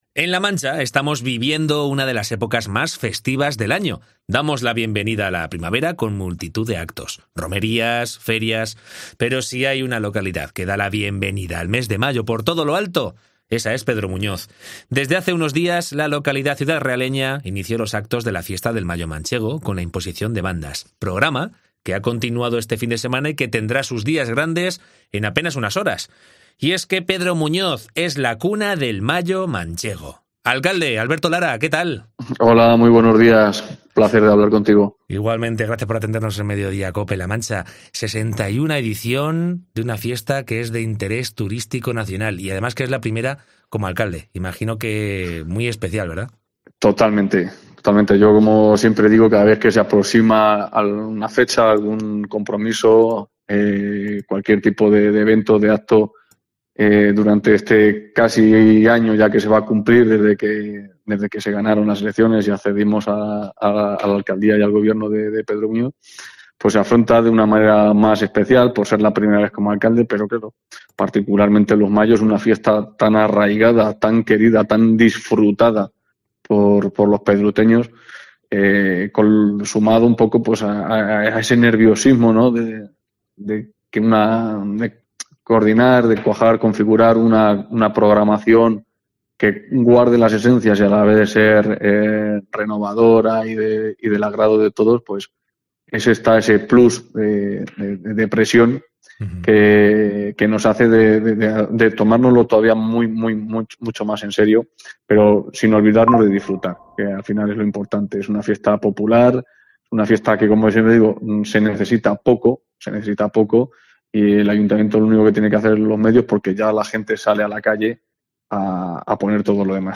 Entrevista con Alberto Lara, alcalde de Pedro Muñoz en la previa de la LXI Fiesta del Mayo Manchego